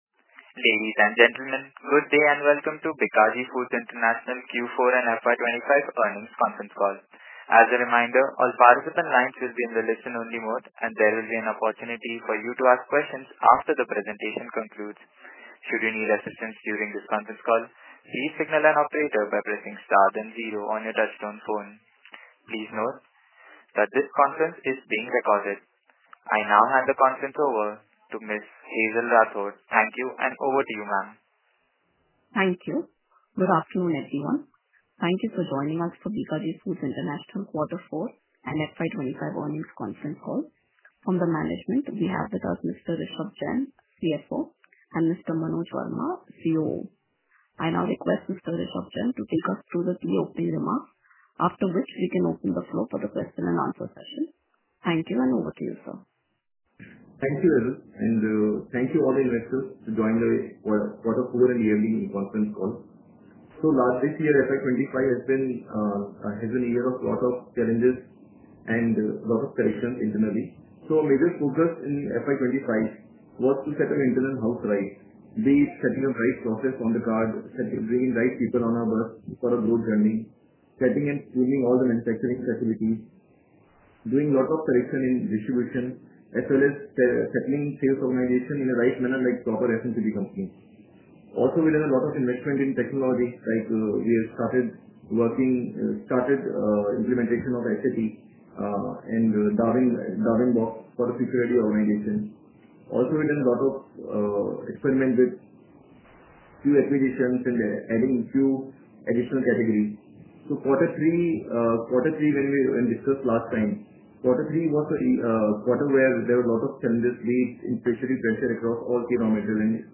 Concalls
Bikaji-Foods-International-Q4FY25-Earnings-Call.mp3